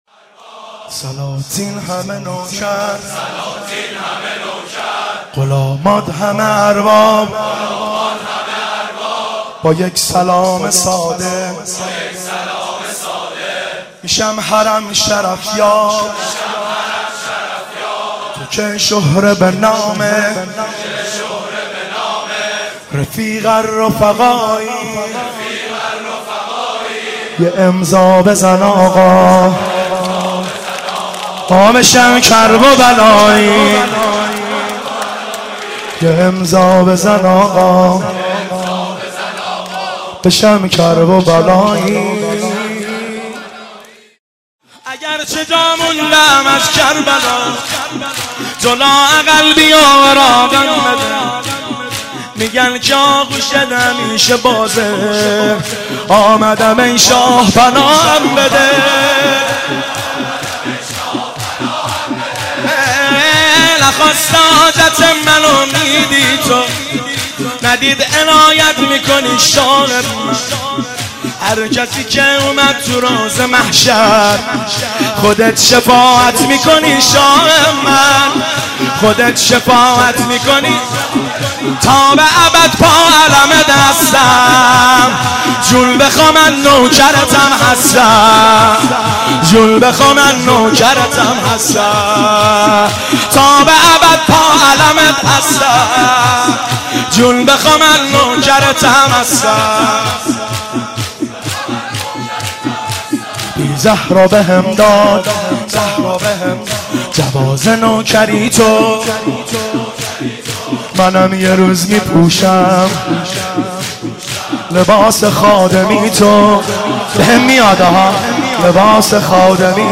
شور- سلاطین همه نوکر غلامان همه ارباب
شهادت امام رضا(ع)